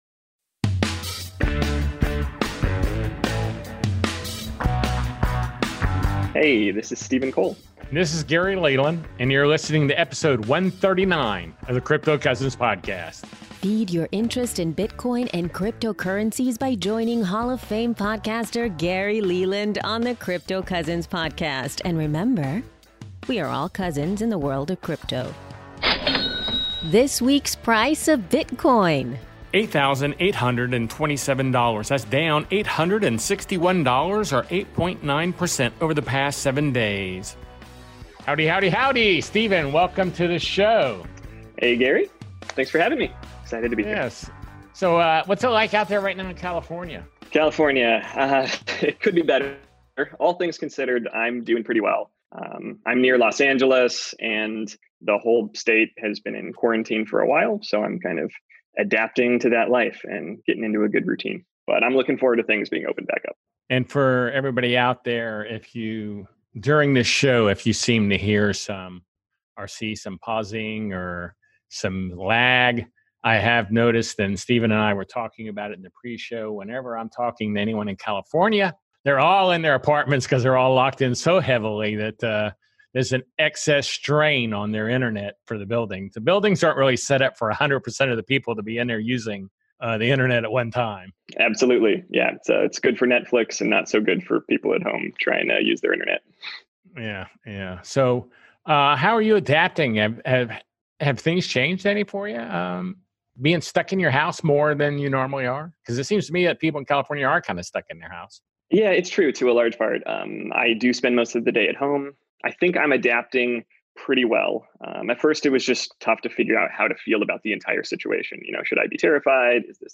Into and outro voice over